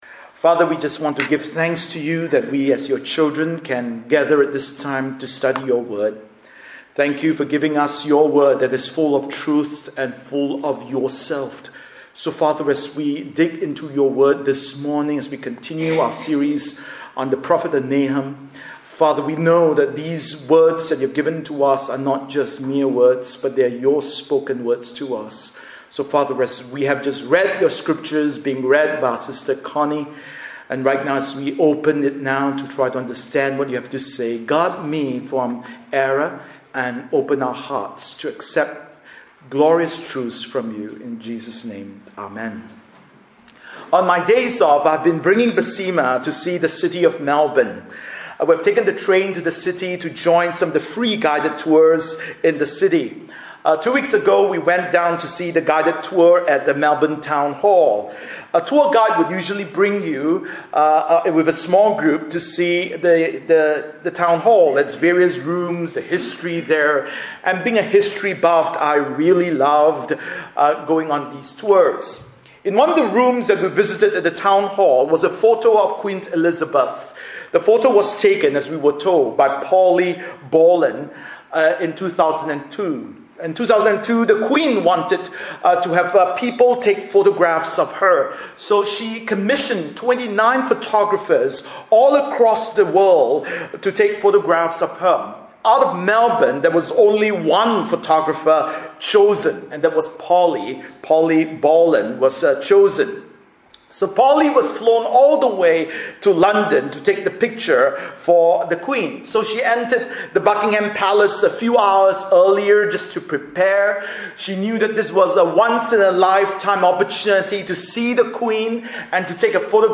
Bible Text: Nahum 1:15-2:7 | Preacher